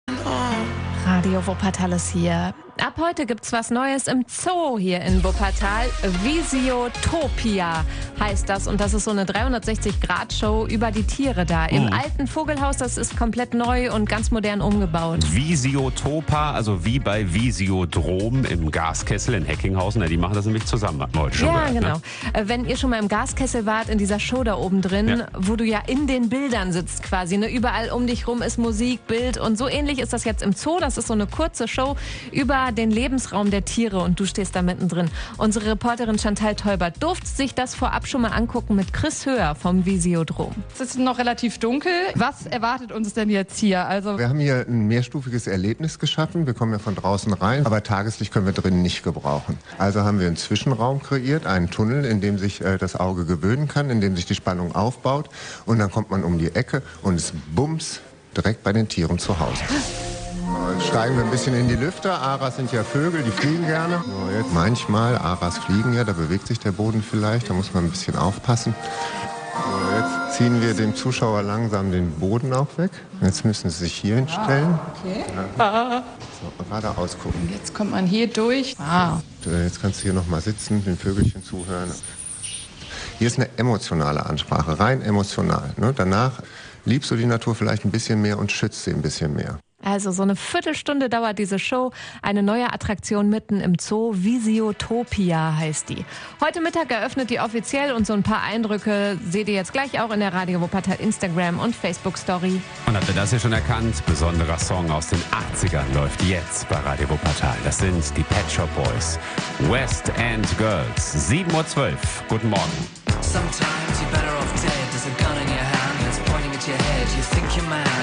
Unsere Reporterin durfte vorab rein.
Veröffentlicht: Mittwoch, 16.07.2025 10:04 Anzeige play_circle play_circle Visiotopia: Reportage download play_circle Abspielen download Anzeige Anzeige